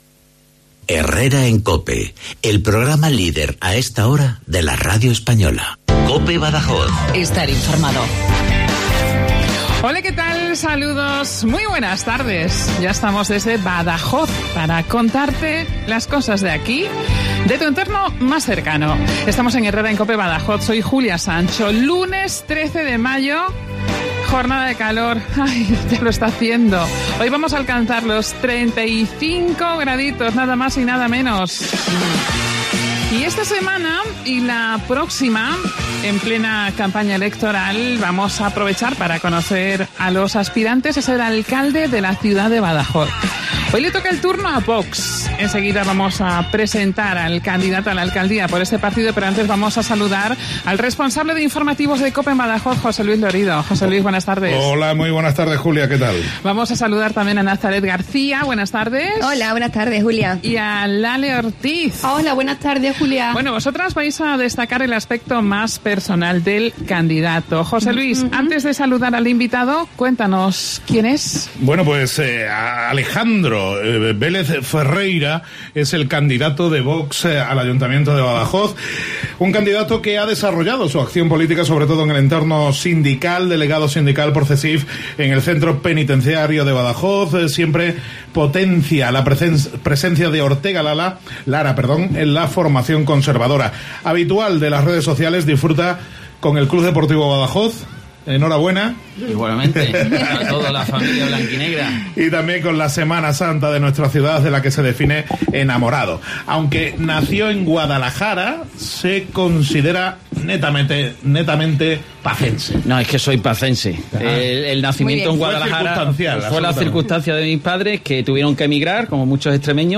En Herrera en COPE Badajoz comenzamos una ronda de entrevistas con los candidatos de los distintos partidos políticos a la alcaldía de Badajoz.Hoy, hemos invitado a VOX.